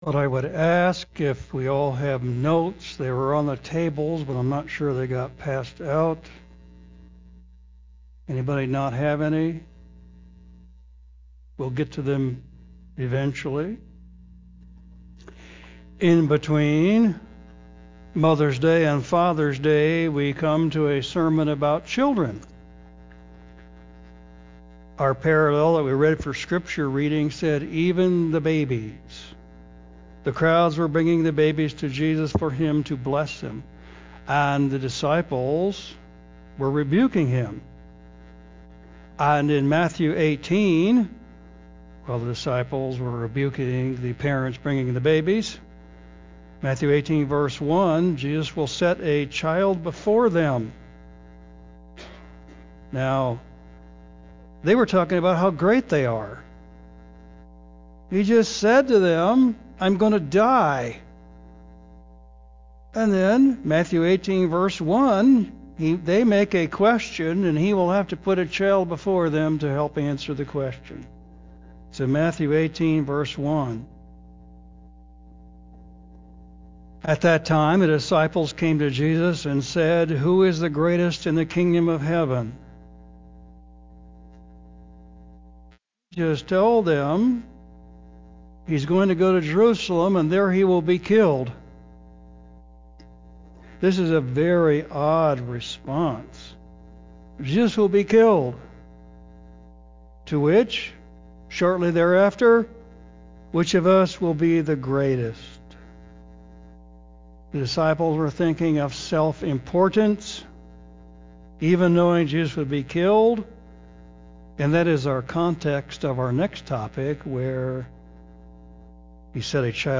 Westcliff Bible Church Weekly Sermons available in MP3 audio, hit play and listen to the latest sermon